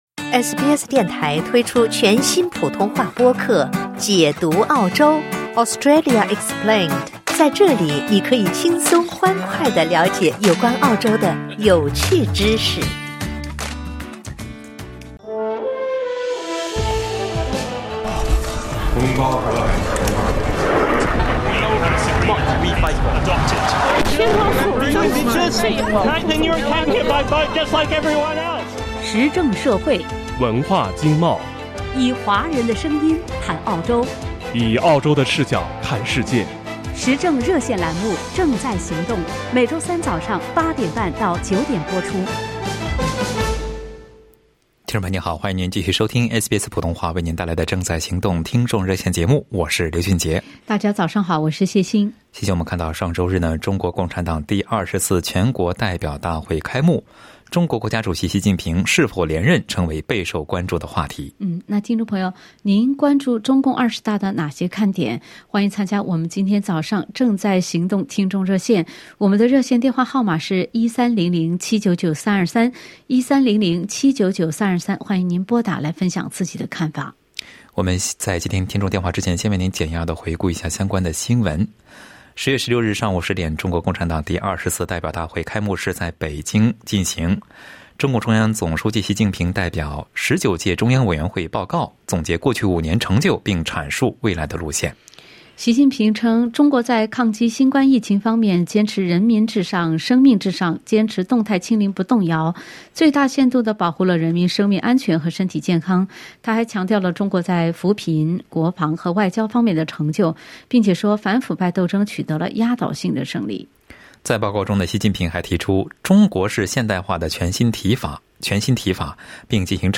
在本期《正在行动》听众热线节目中，听友们就中共二十大的看点分享了看法。